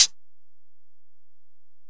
Closed Hats